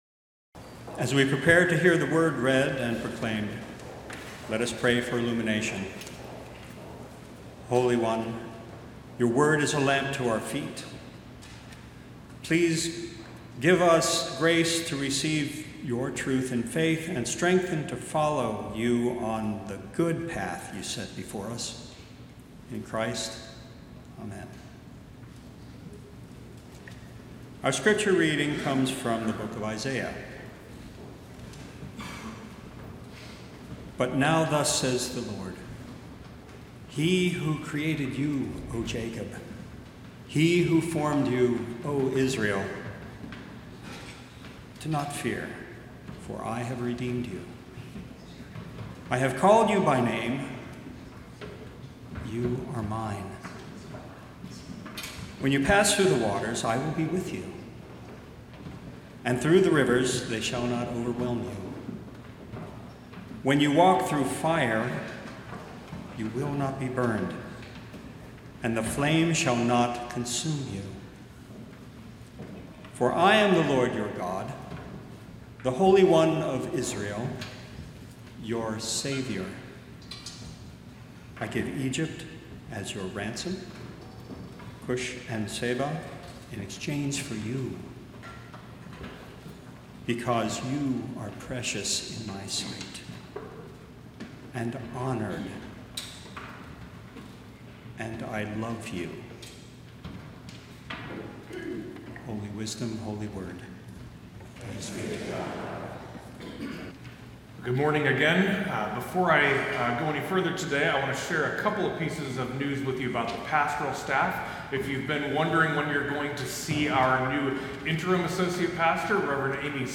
Sermon-Sept-7-2025-Welcome-Home.mp3